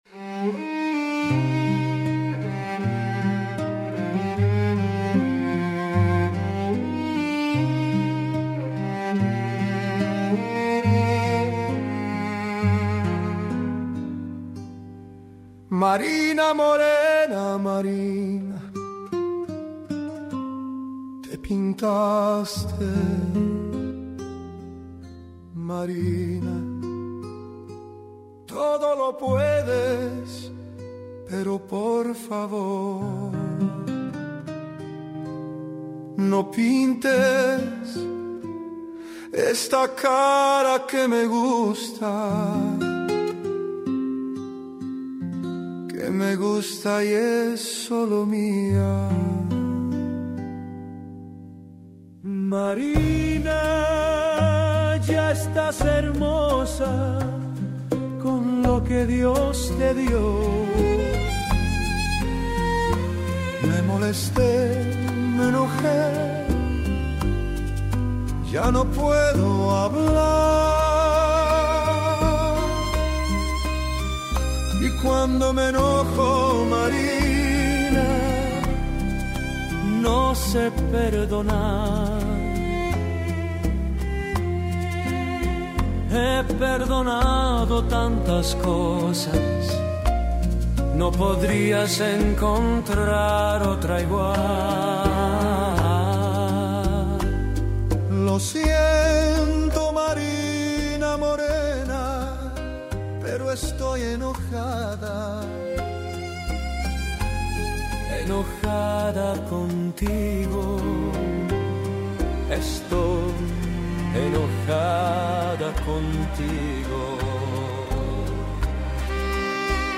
música e arranjo: IA